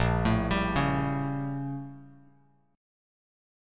A#mM13 chord